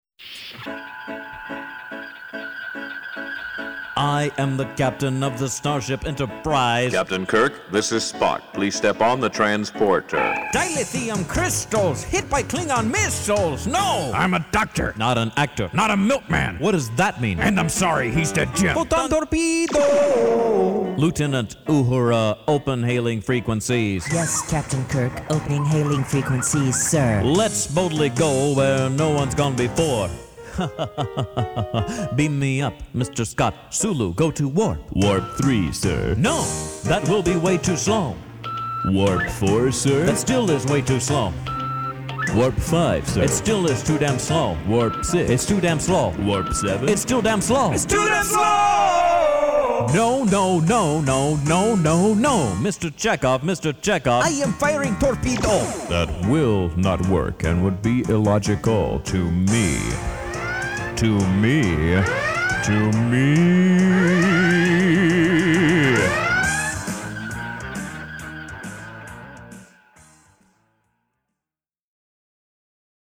Písničkovou parodii na Star Trek